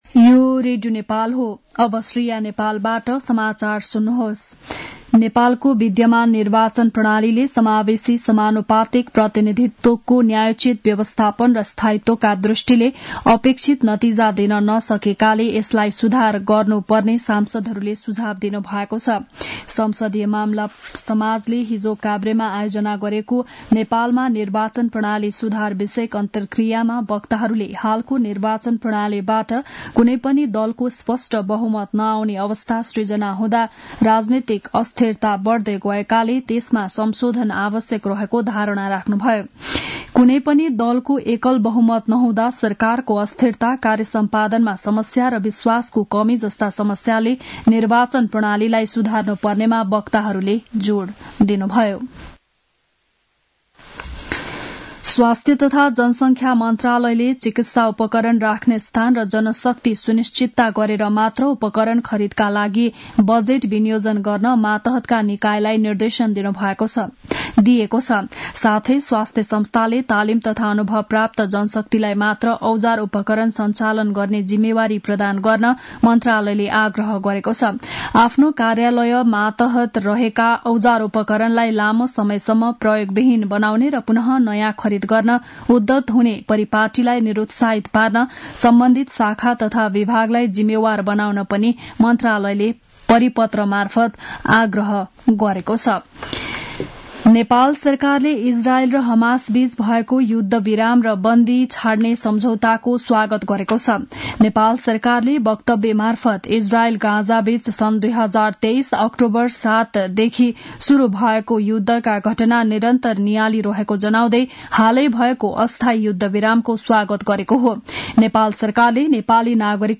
बिहान ११ बजेको नेपाली समाचार : ७ माघ , २०८१
11-am-news-1-8.mp3